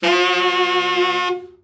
Index of /90_sSampleCDs/Giga Samples Collection/Sax/TENOR OVERBL
TENOR OB  12.wav